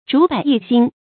竹柏异心 zhú bǎi yì xīn
竹柏异心发音